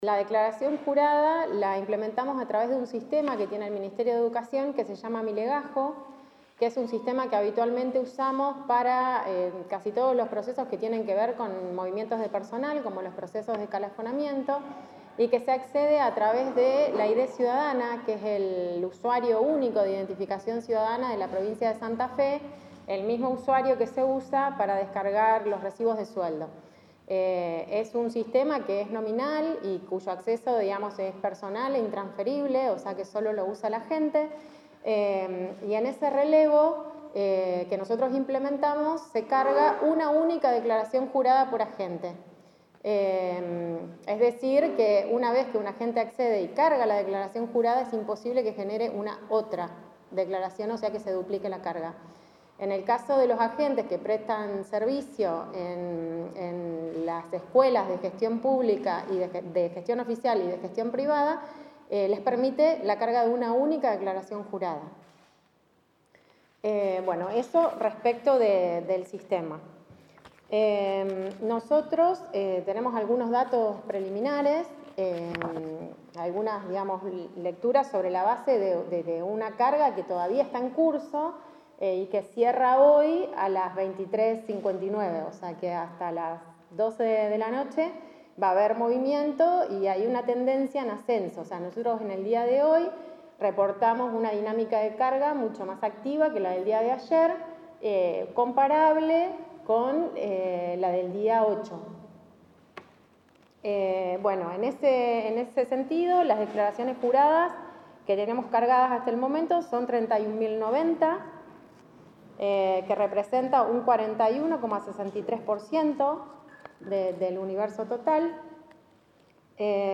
Bacolla y Goity